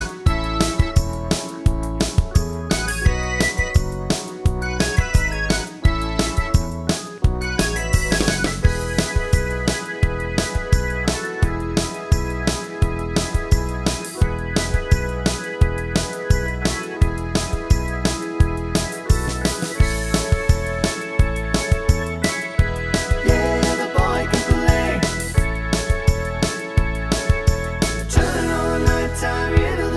No Guitars Pop (1980s) 3:38 Buy £1.50